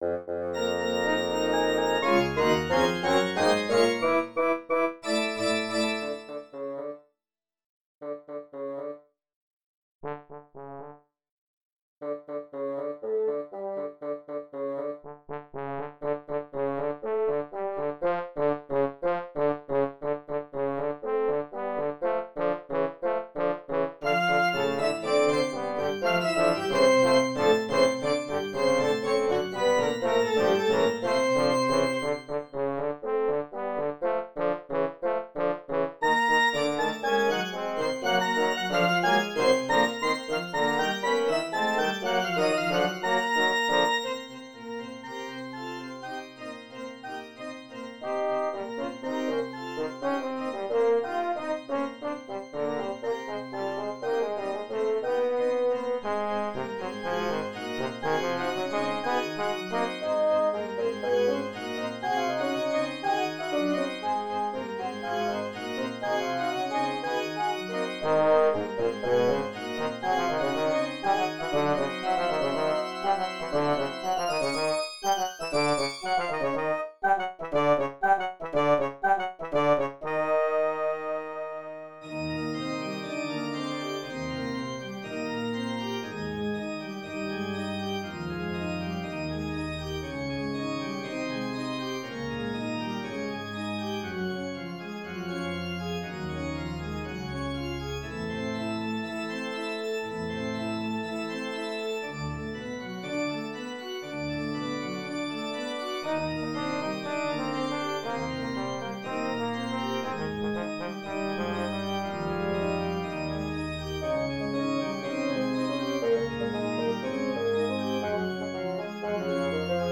Here’s an embed of my software playing my piece. It’s crappy but I thought one of you might be curious enough to listen to it.
Breath-Dance-sung-by-finale-software.mp3